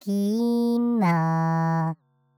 sounds like granular synthesis may be?